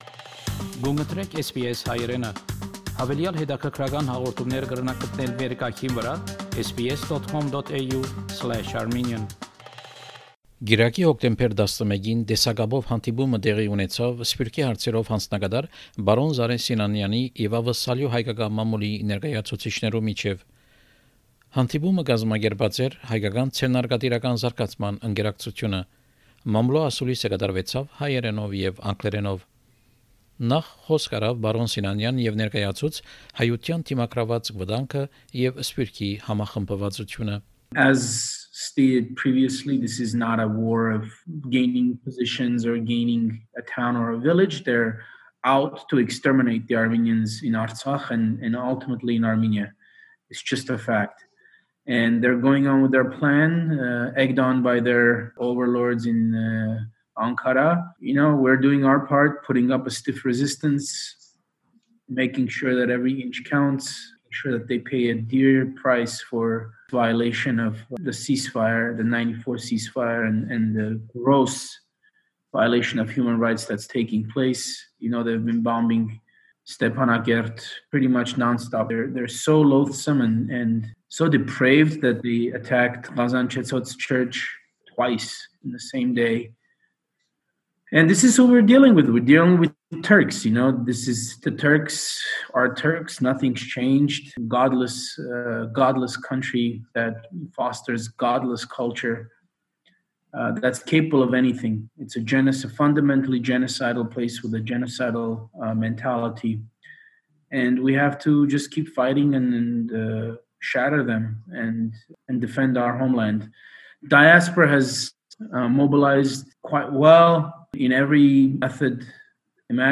Press conference of Mr Zareh Sinanyan
Media conference of Mr Zareh Sinanyan, High Commissioner for Diaspora Affairs, with Australian Armenian media. The media conference was organised by the Armenian Business Development Association.